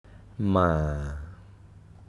Tone: low and calm
ToneMidLowFallingHighRising
Phoneticmaamàamâamáamǎa